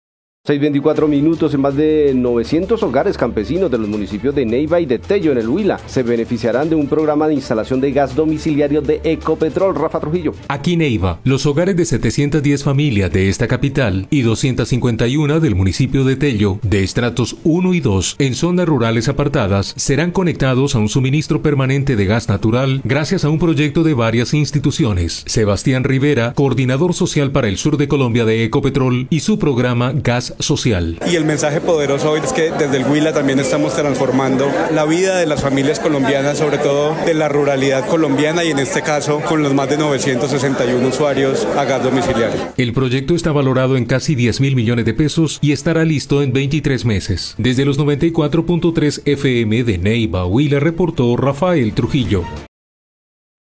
Nota RTVC sobre firma de convenio de gas social en Huila.mp3